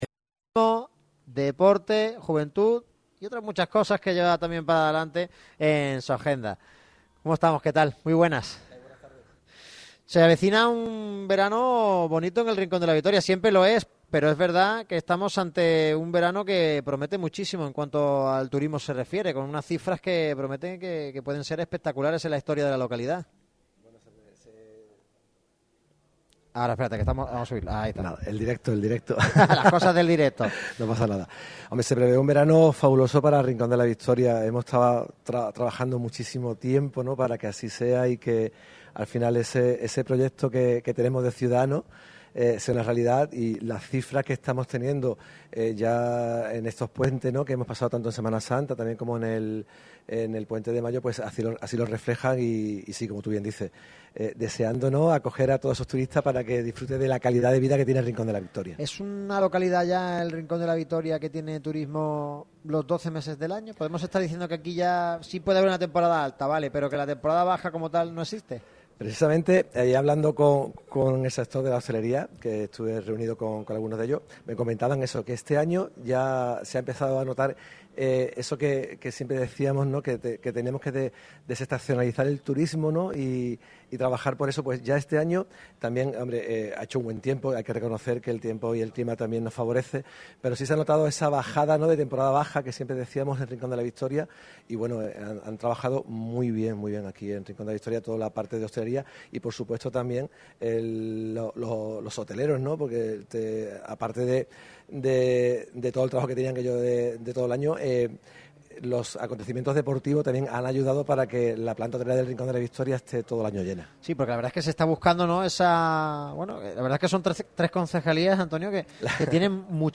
Antonio José Martín, concejal de Turismo, Deporte y Juventud del Ayuntamiento de Rincón de la Victoria pasó revista por el micrófono rojo de Radio MARCA Málaga en el Asador La Casa. El edil habló de los asuntos más importantes en el área turística y deportiva del municipio.